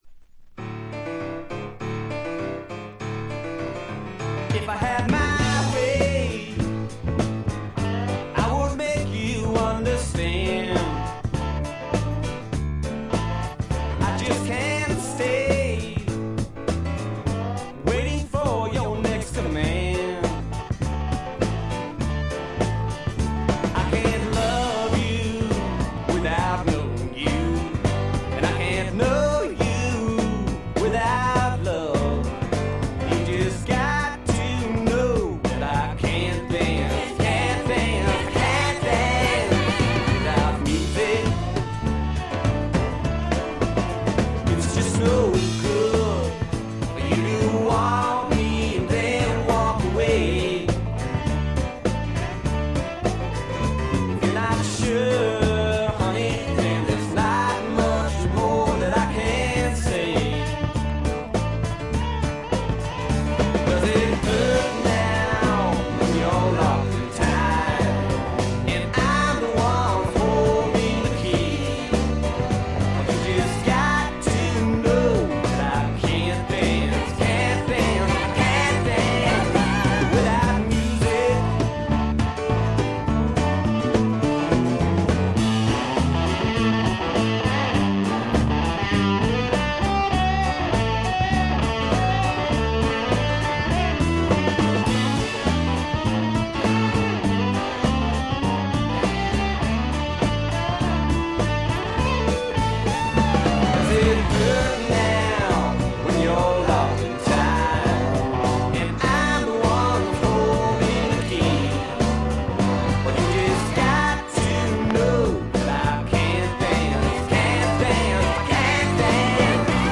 これ以外は軽いチリプチ少々、散発的なプツ音2-3回という程度で良好に鑑賞できると思います。
試聴曲は現品からの取り込み音源です。
Recorded at Larrabee Sound , Holywood , California